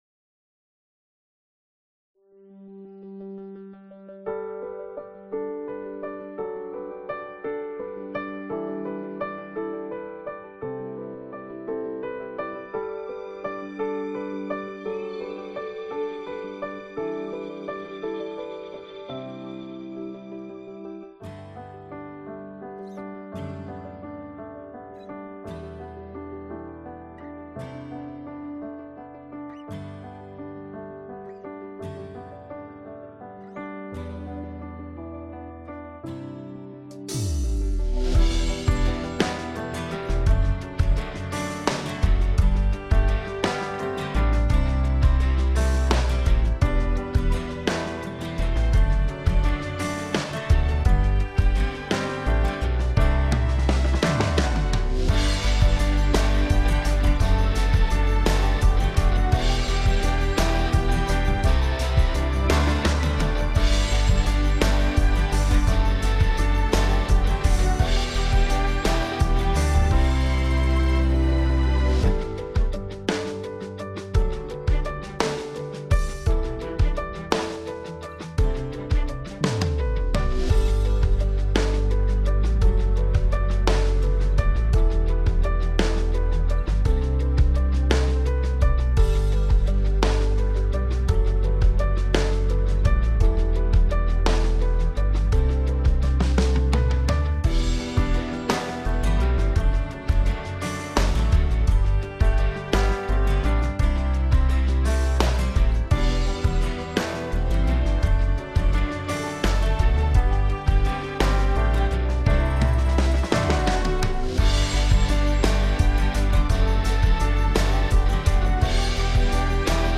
Minus One